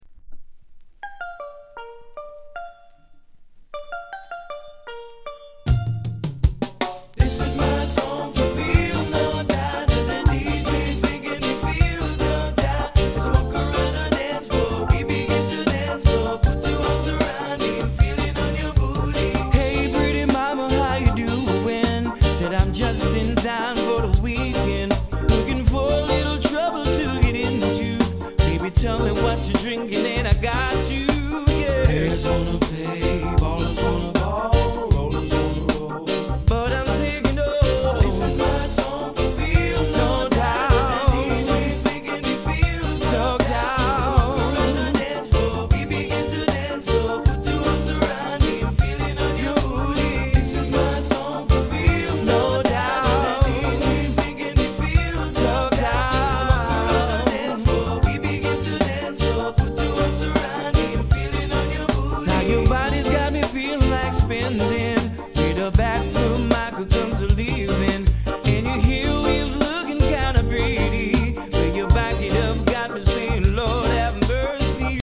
category Reggae